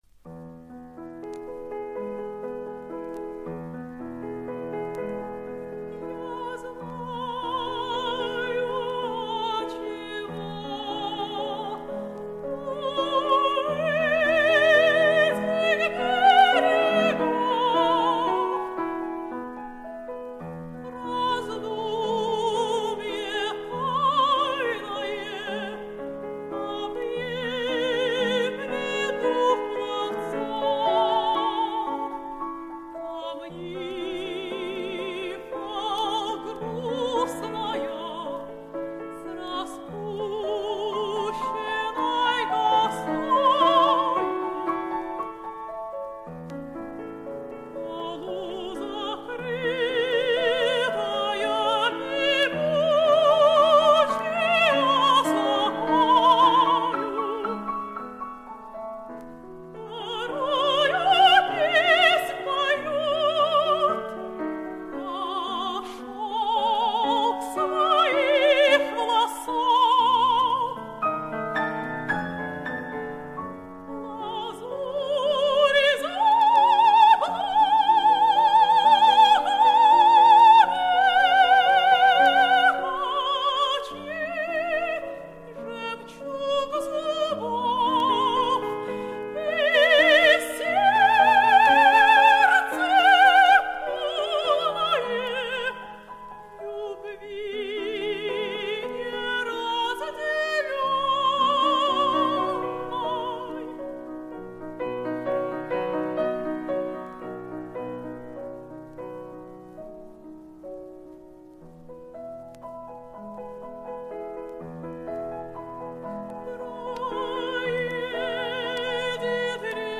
Романс «Нимфа», датированный 7 сентября 1898 года, Римский-Корсаков посвятил Врубелям.